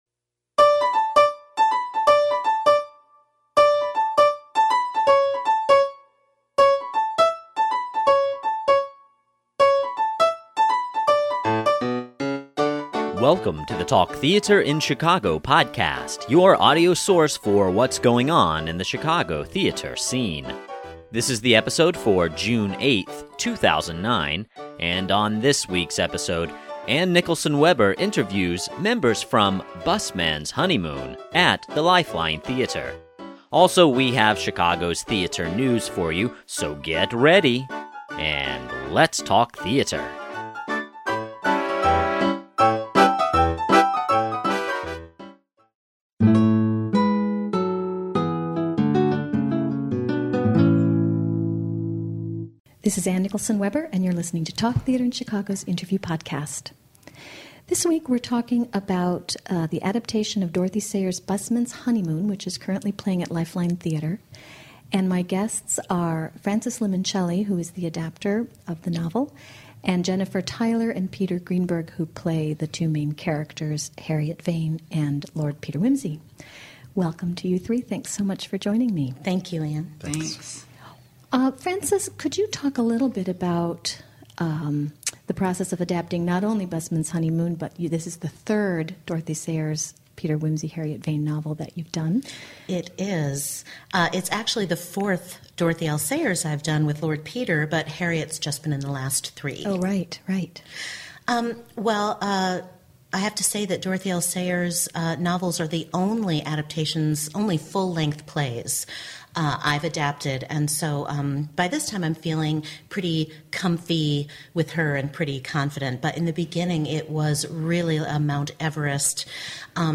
Busman's Honeymoon Interview Podcast